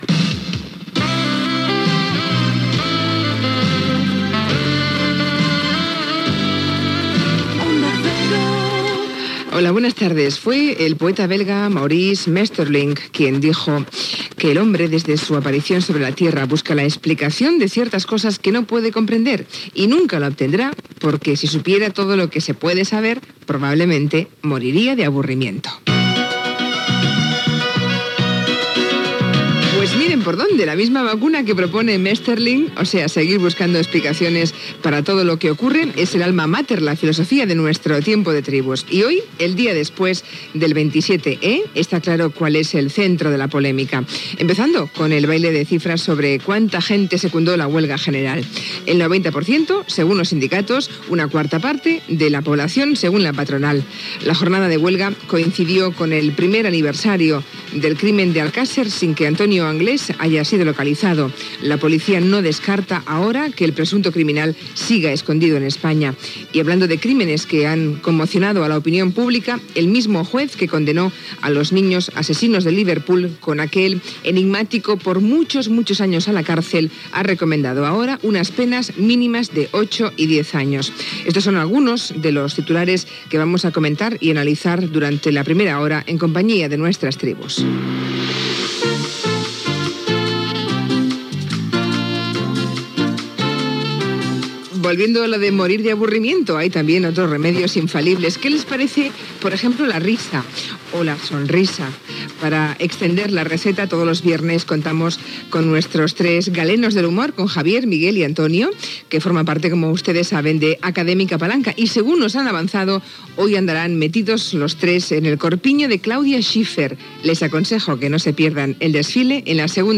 Indicatiu de l'emissora, presentació, repàs a l'actualitat (vaga general a Espanya), sumari de continguts, indicatiu, publicitat, secció "Las tribus"
Info-entreteniment